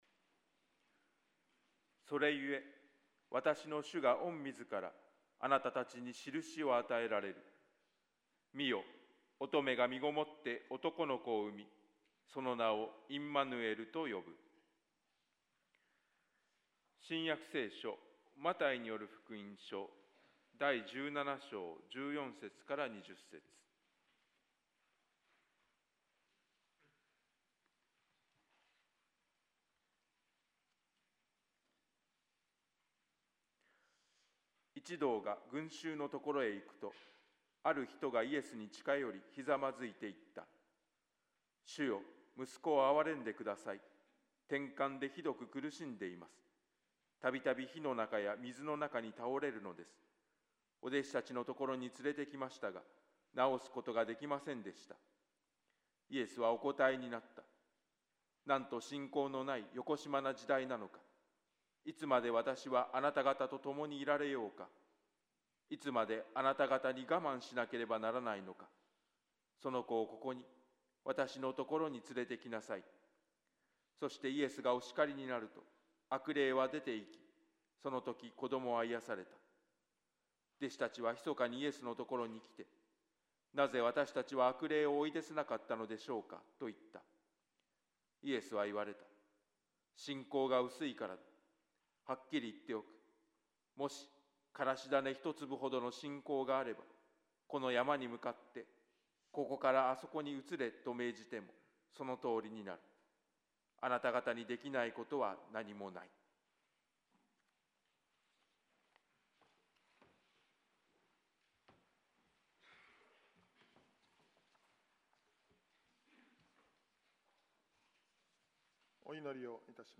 説 教 「共にいて下さる神」